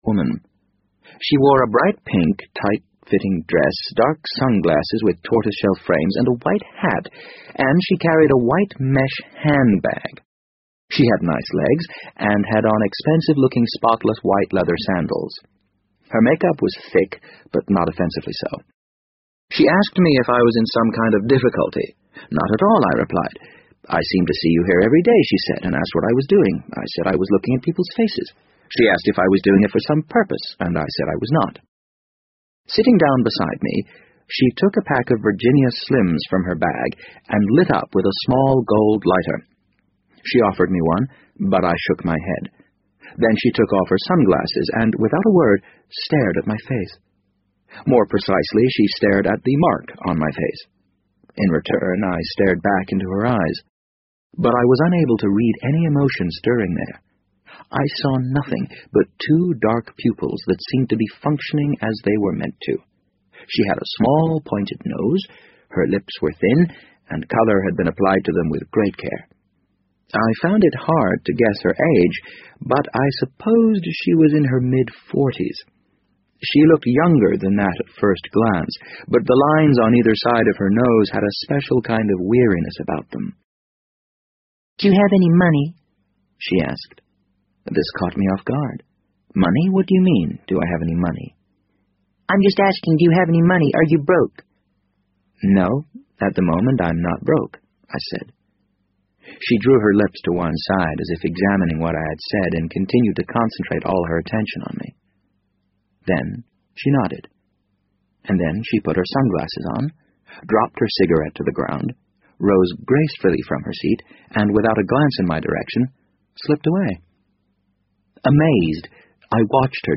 BBC英文广播剧在线听 The Wind Up Bird 009 - 1 听力文件下载—在线英语听力室